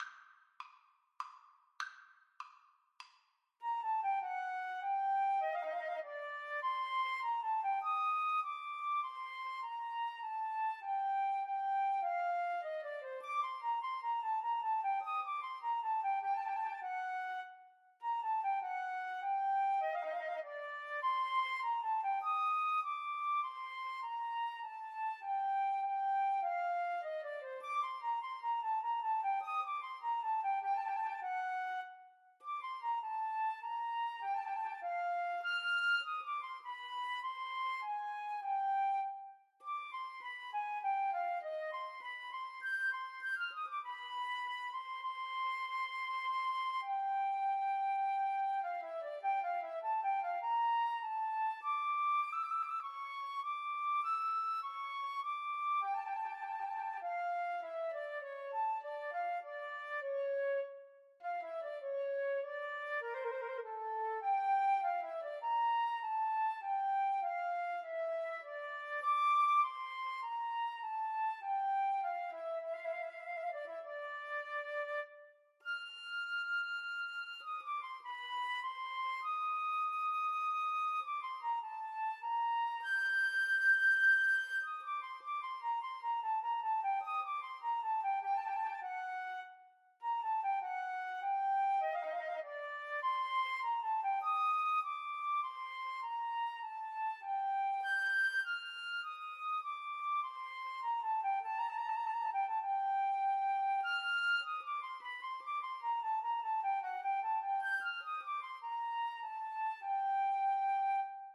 Tempo di menuetto
3/4 (View more 3/4 Music)
Flute Duet  (View more Intermediate Flute Duet Music)
Classical (View more Classical Flute Duet Music)